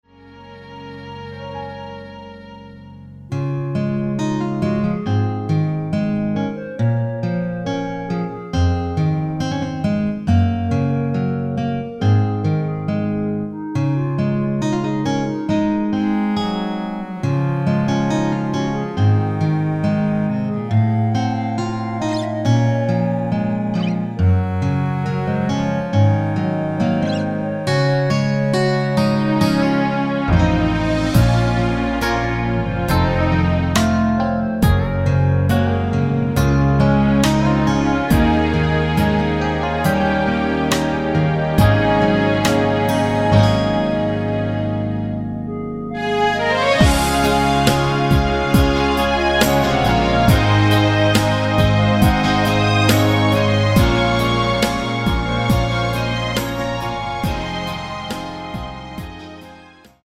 Db
노래방에서 노래를 부르실때 노래 부분에 가이드 멜로디가 따라 나와서
멜로디 MR이라고 합니다.
앞부분30초, 뒷부분30초씩 편집해서 올려 드리고 있습니다.